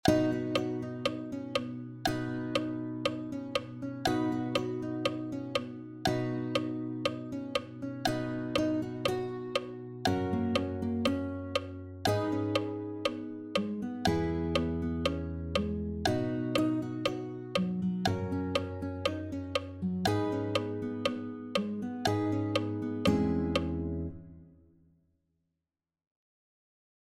"Drive" uses a 1/5/6/4 chord progression in B: B, C#, D#, E, F# G# A# and B. The 1/5/6/4 progression then for B will be B (1) , F# (5) , G#m (6) and E (4).